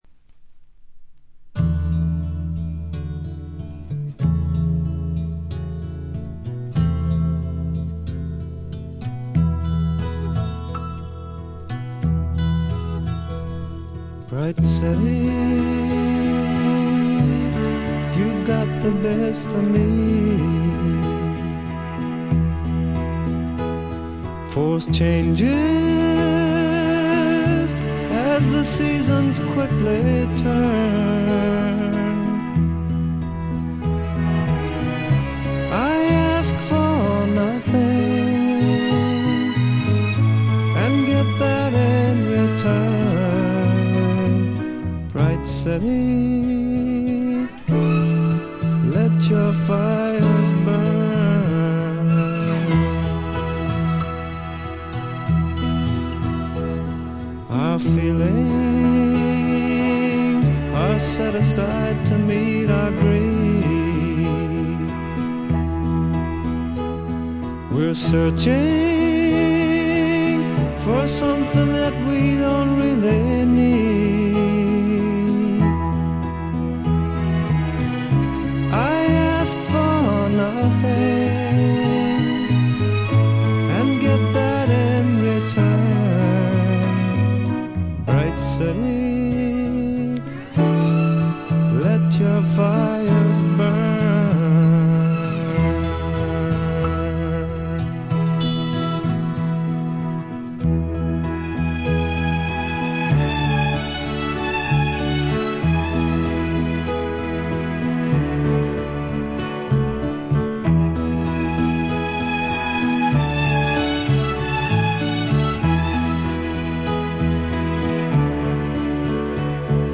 軽微なチリプチ少し。
試聴曲は現品からの取り込み音源です。